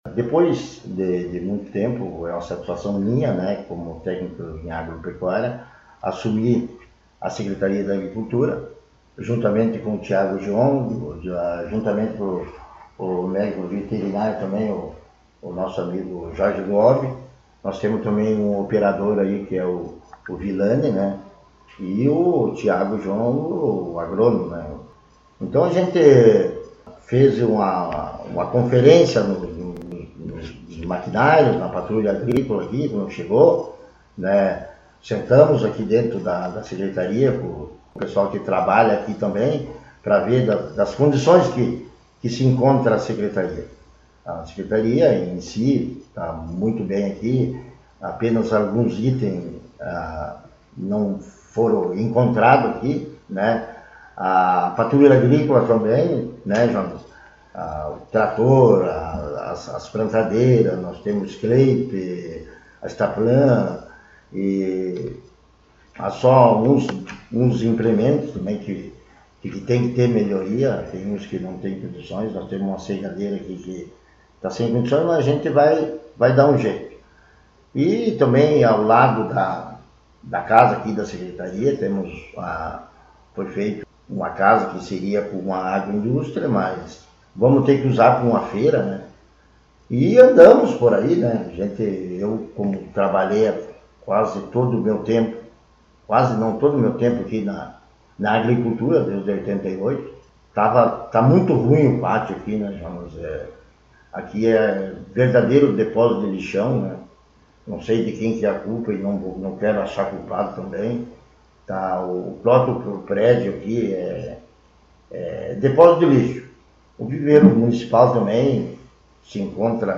Secretário Municipal de Agricultura e funcionários concederam entrevista